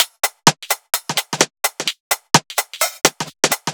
Index of /musicradar/uk-garage-samples/128bpm Lines n Loops/Beats